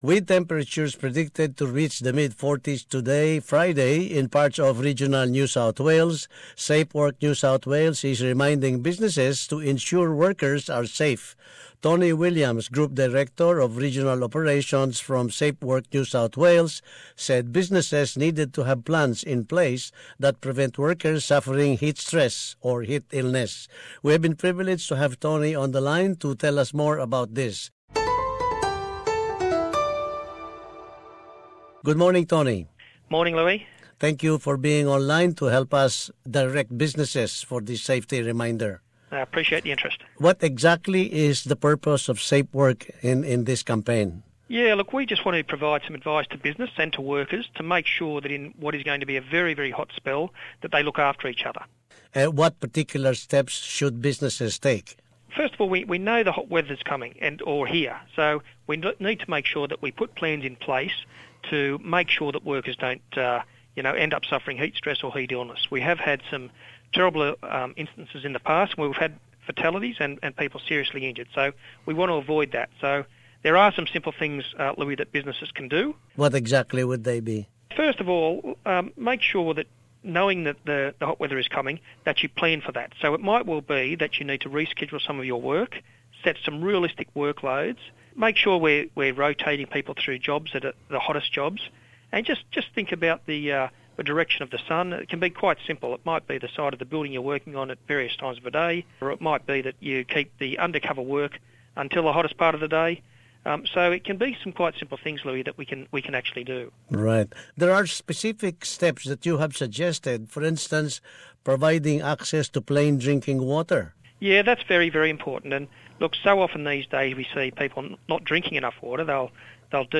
We spoke with an official of Safeworks New South Wales to learn how to escape the heat, in both personal and corporate ways.